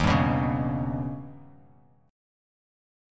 D#+7 chord